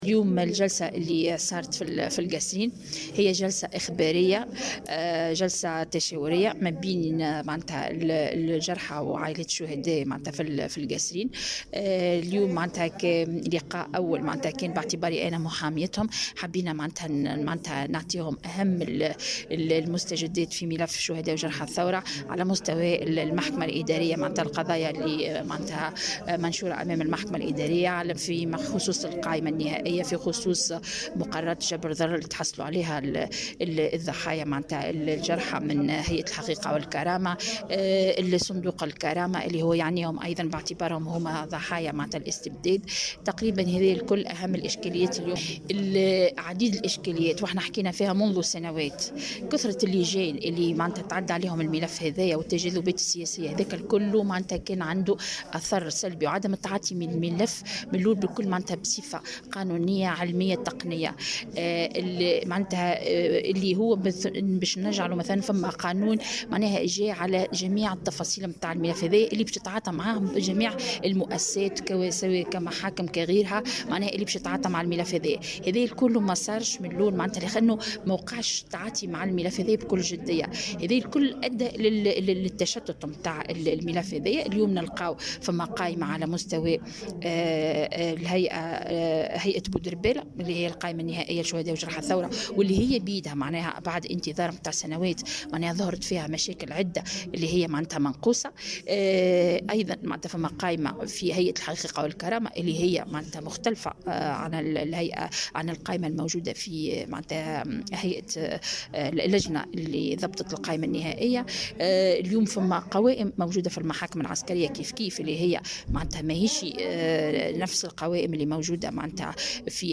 تصريح لراديو السيليوم اف ام